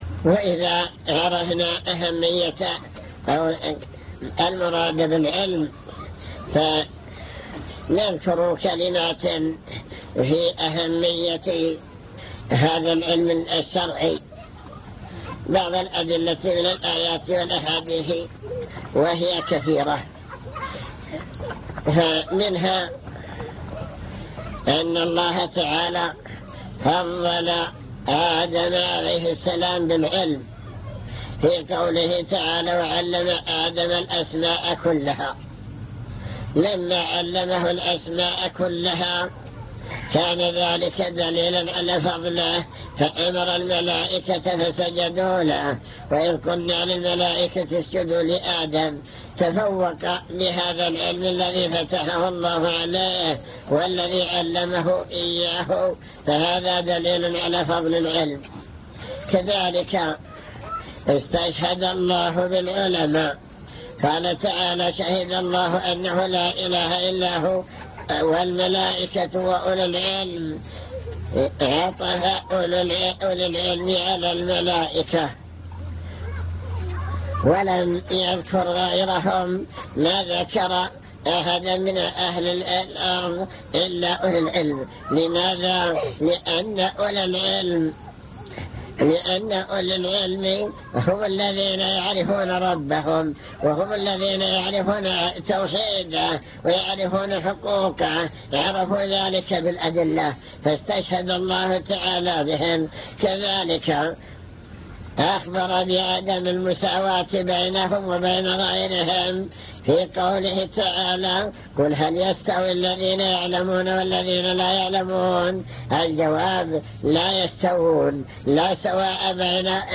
المكتبة الصوتية  تسجيلات - محاضرات ودروس  محاضرات عن طلب العلم وفضل العلماء بحث في: أهم المسائل التي يجب على المسلم تعلمها